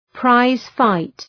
Προφορά
prize-fight.mp3